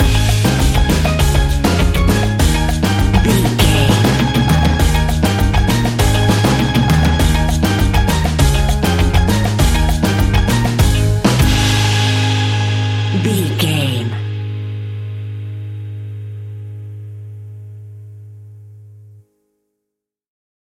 Uplifting
Ionian/Major
A♭
steelpan
drums
percussion
bass
brass
guitar